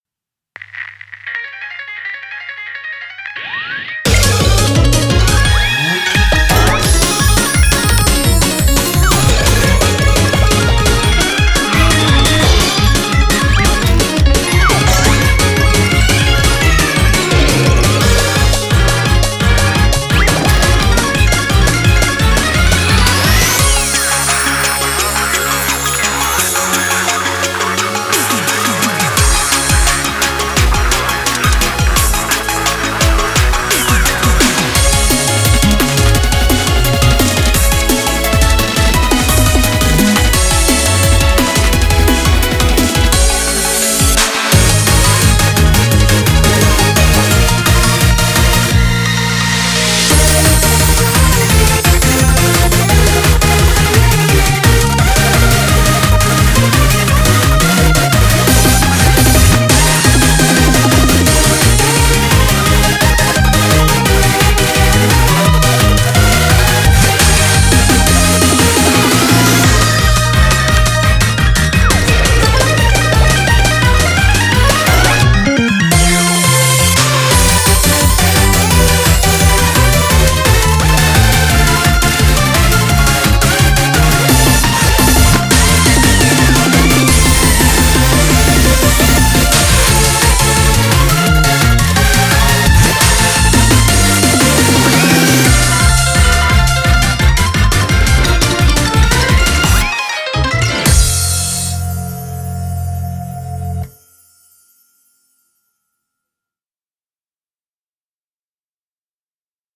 BPM86-172
Audio QualityPerfect (High Quality)
Genre: RAGTIME FUTURE POP.
A cute, energetic song.